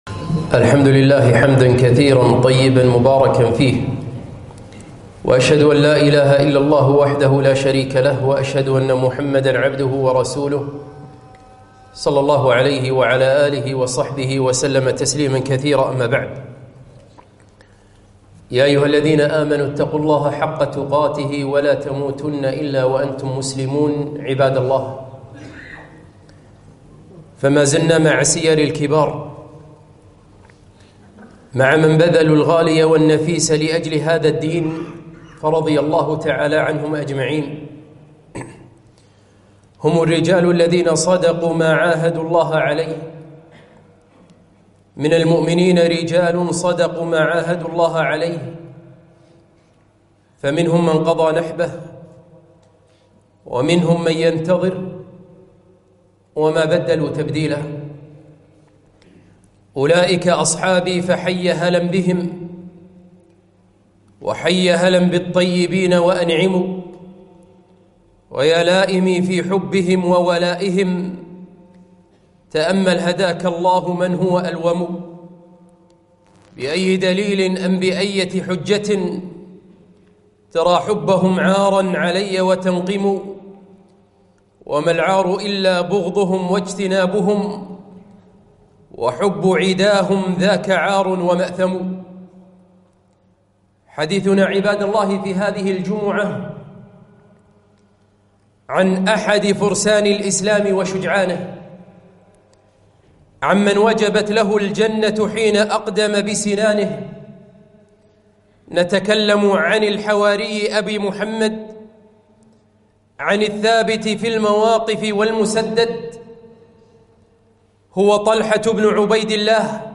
خطبة - طلحة بن عبيدالله رضي الله عنه